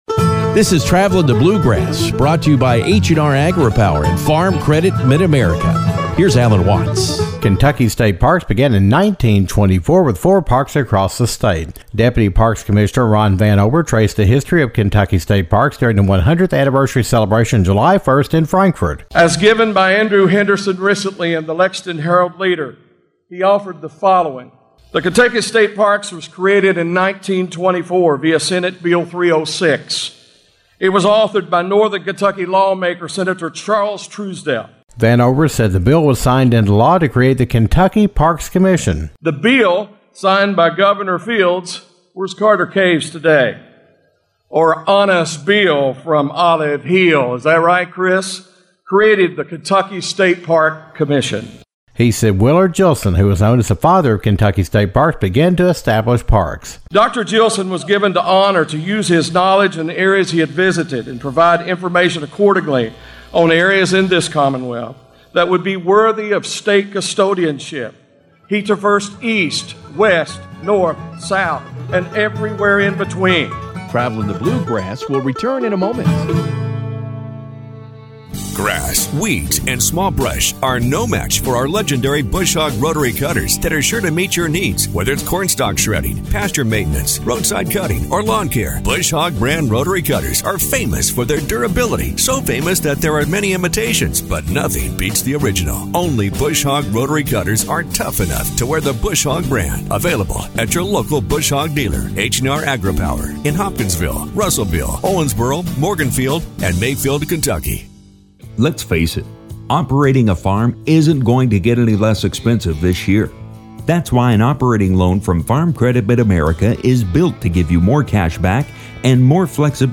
During the Kentucky State Parks 100-year celebration on July 1st in Frankfort Deputy Kentucky Parks Commissioner Ron Vanover discussed the history of Kentucky State Parks. Vanover talked about the father of Kentucky State Parks Willard Jillson and the first four parks that were formed in 1924.